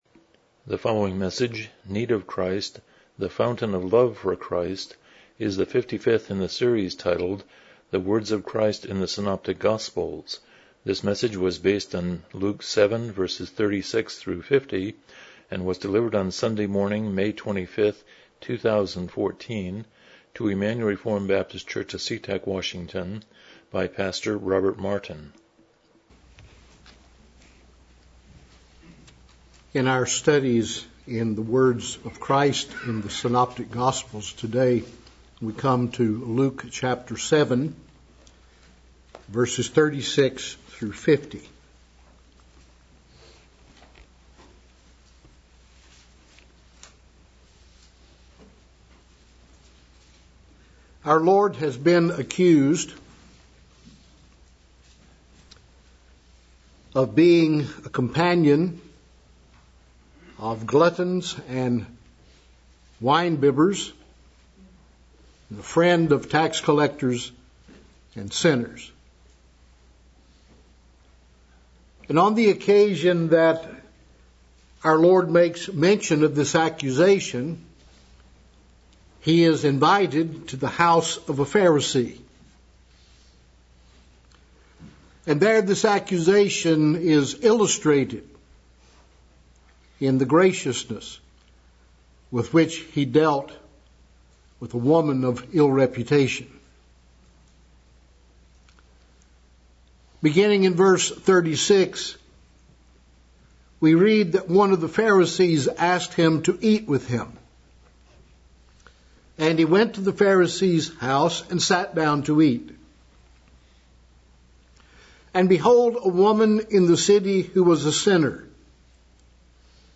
Passage: Luke 7:36-50 Service Type: Morning Worship